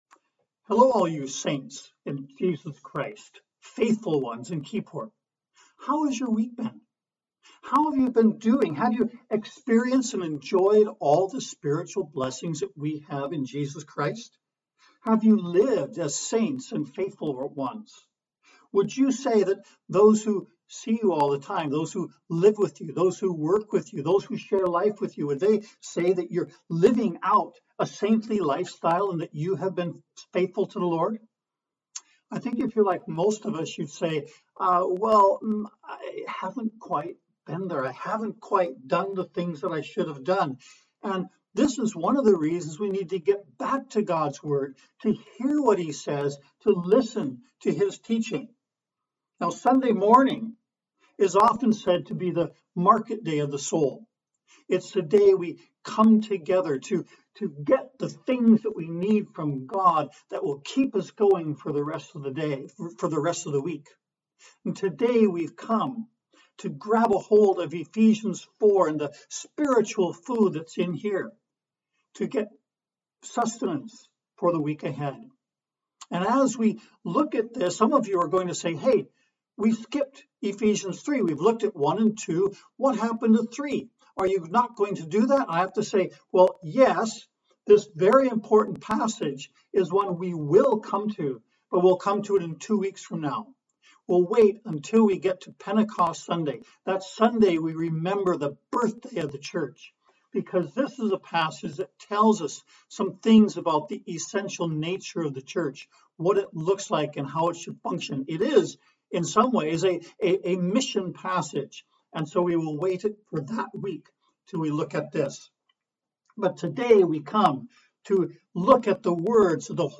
Prelude
Announcements and Scripture Reading